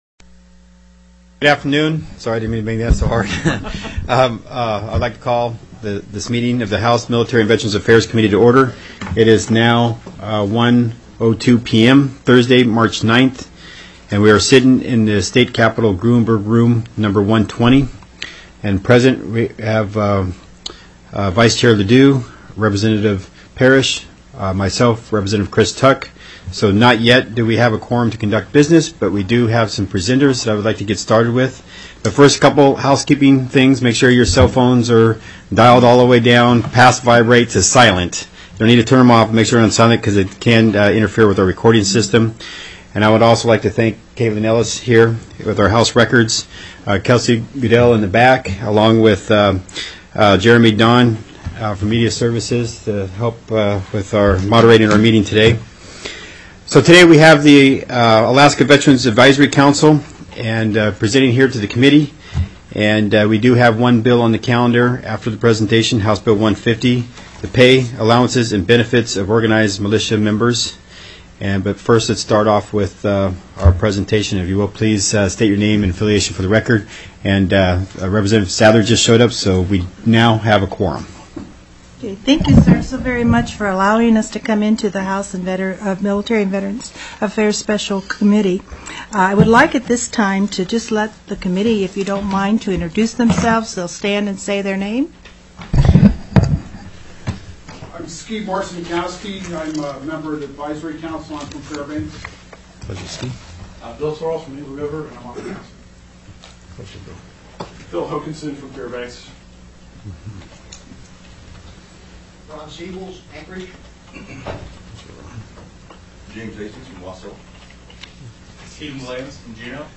03/09/2017 01:00 PM House MILITARY & VETERANS' AFFAIRS
The audio recordings are captured by our records offices as the official record of the meeting and will have more accurate timestamps.